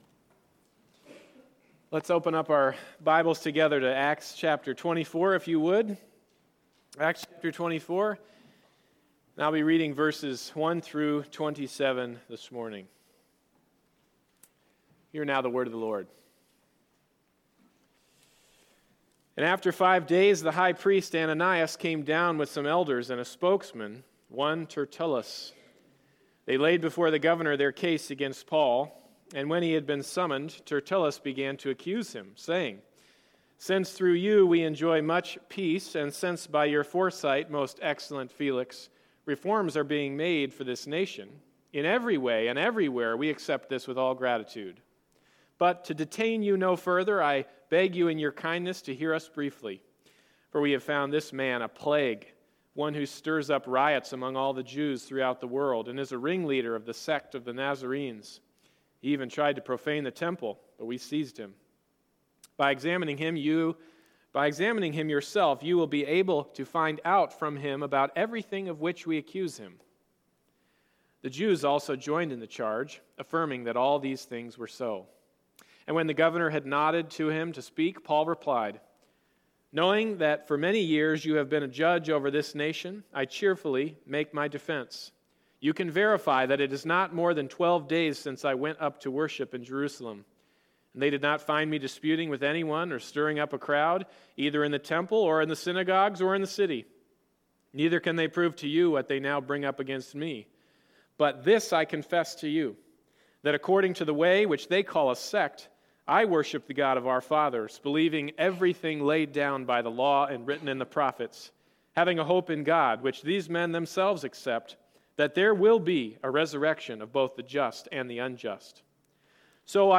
Service Type: Sunday Morning Service